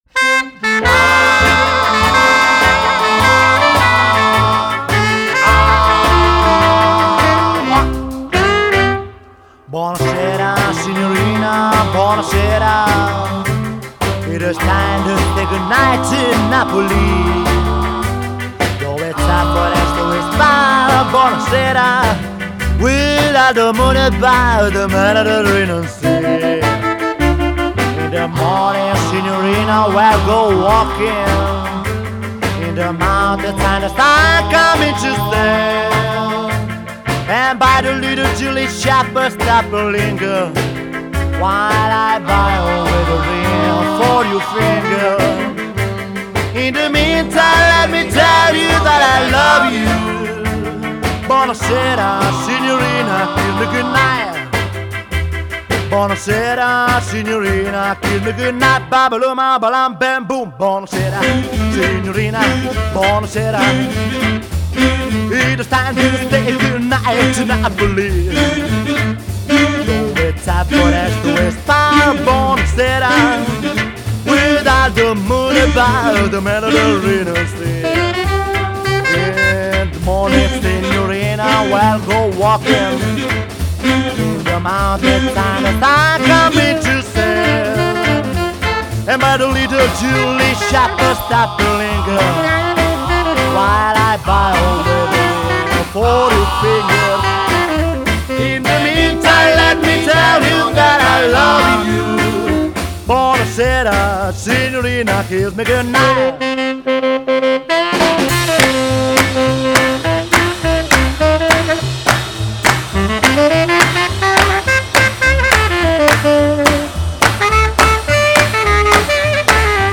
Genre: Pop, Jazz, Rock'n'roll